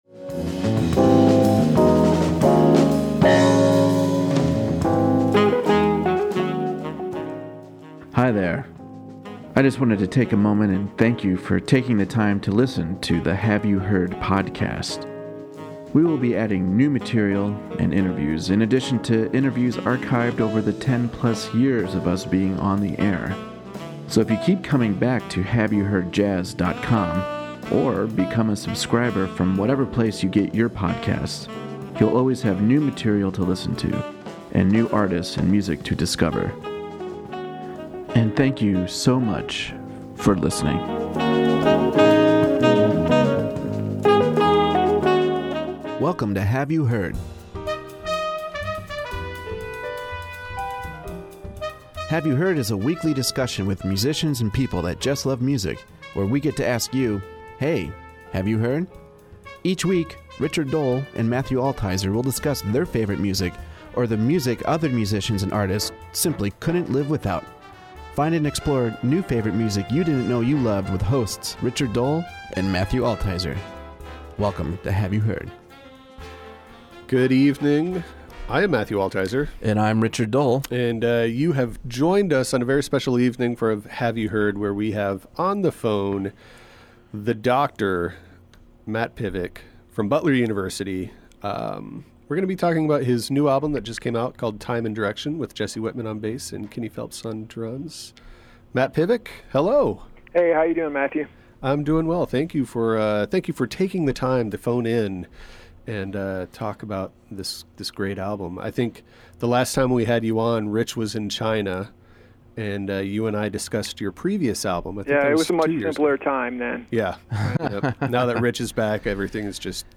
bass
drums
power trio